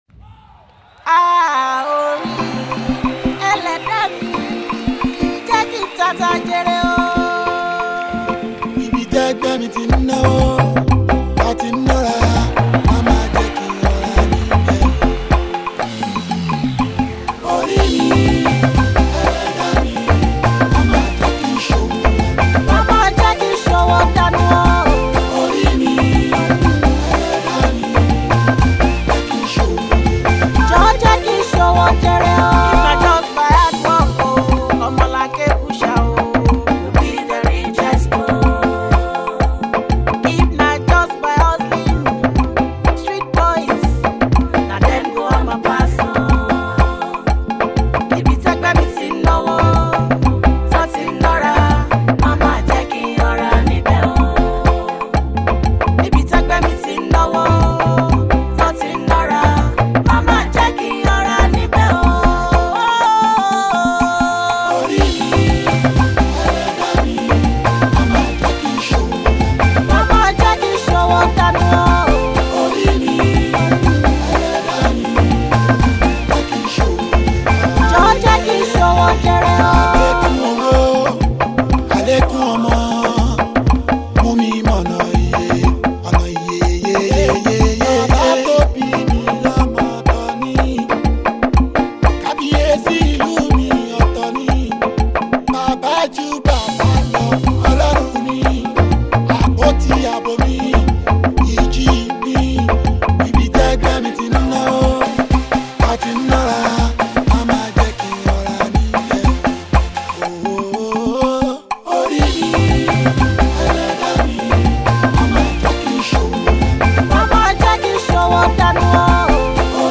Indigenous Pop, Yoruba Music
Trado-Pop